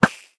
Minecraft Version Minecraft Version 25w18a Latest Release | Latest Snapshot 25w18a / assets / minecraft / sounds / entity / bobber / castfast.ogg Compare With Compare With Latest Release | Latest Snapshot